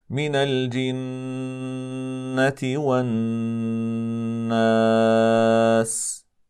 Det ska uttalas på följande sätt: